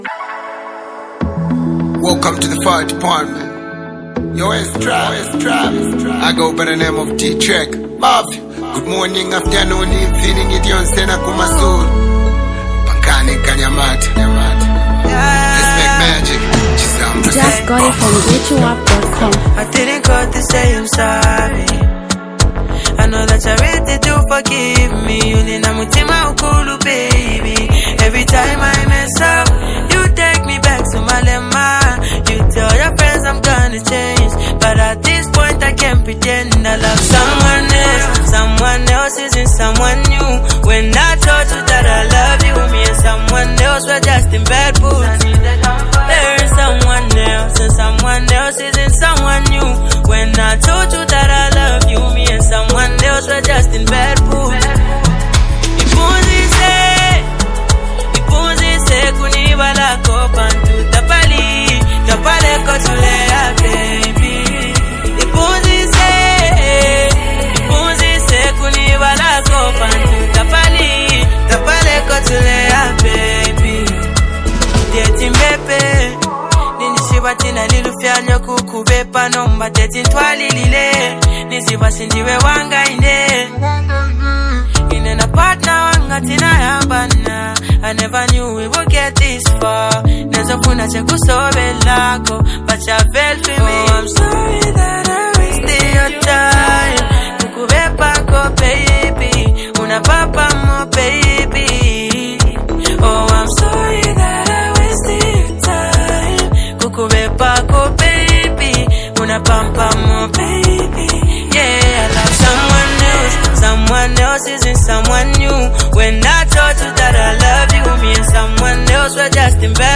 dynamic vocals.
a catchy, rhythm-driven track
Zambian music
Afro-pop, Dancehall.